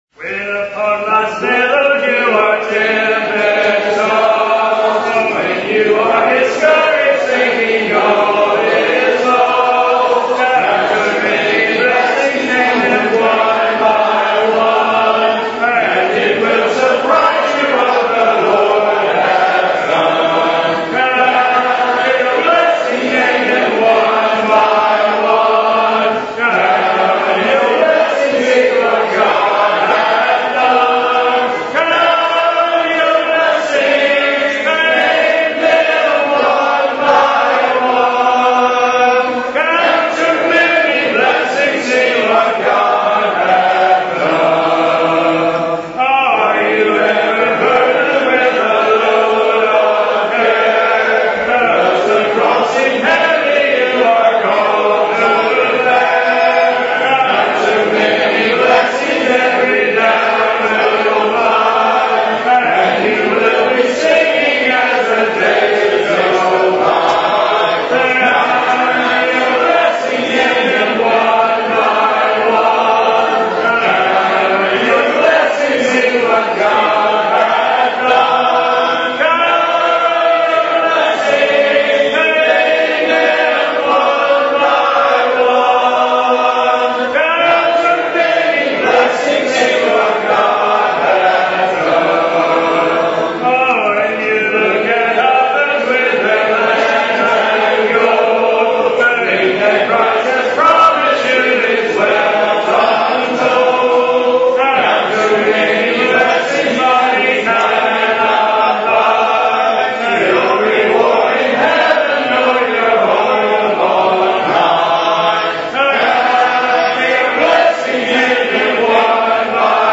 Part (B) Congregational Singing at 2018 Ebenezer Fellowship Meeting Hosted by Trail Branch PBC (Rev)
Service Type: Singing
CongregationalSinging_3areverb_at2018_EbenezerFellowshipMeetingHostedByTrailBranchPBC.mp3